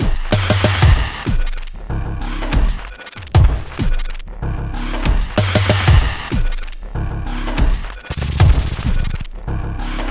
描述：一个带有轻微偏色的节奏的环境节拍。
Tag: 95 bpm Weird Loops Drum Loops 1.70 MB wav Key : Unknown